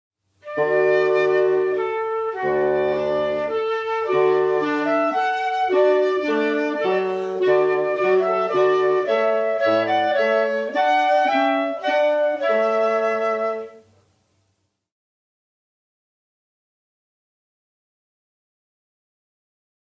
Flute, Clarinet, Bassoon:  2,